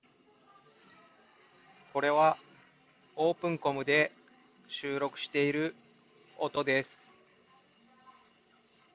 最大の特徴であるブームマイクは、こちらの声をよりクリアに相手に届けます。
同じ音量で環境音を流しつつ収録した音声があるので、違いを聴き比べてみてください。OpenCommが圧倒的にクリアなのがわかるはず。